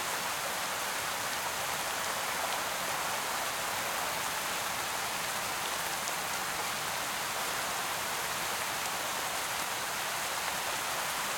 RainLoop1.ogg